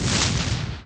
EXPLOSIO / SHIPS / EXP9.WAV